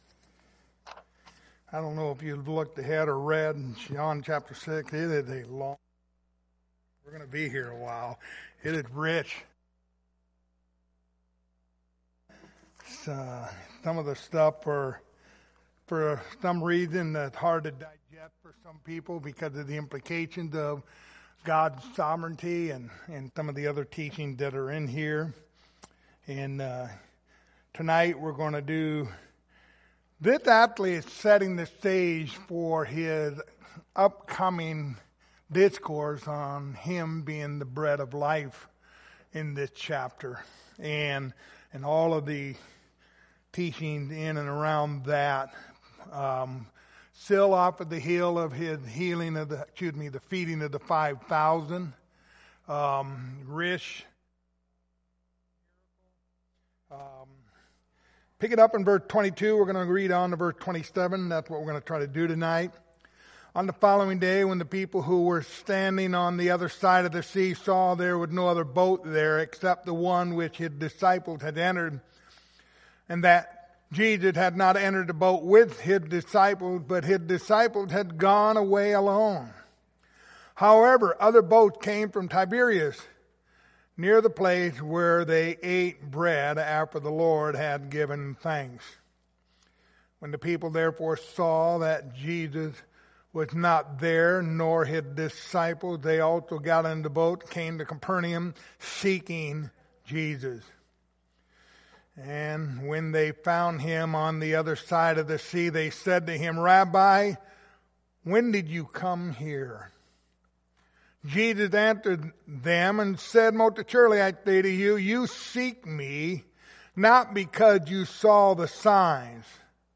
Passage: John 6:22-27 Service Type: Wednesday Evening Topics